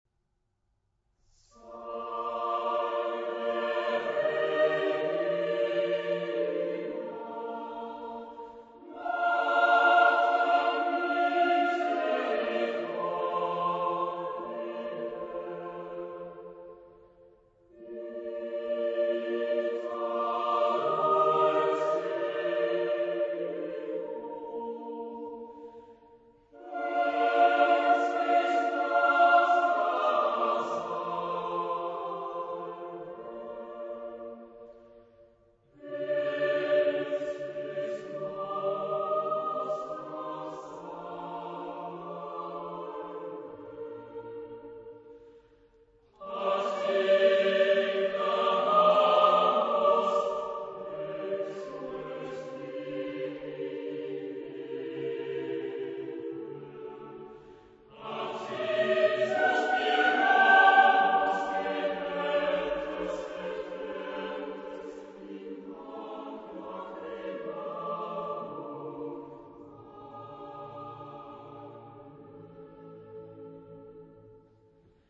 Genre-Stil-Form: romantisch ; geistlich ; Motette
Chorgattung: SATB  (4 gemischter Chor Stimmen )
Tonart(en): B-dur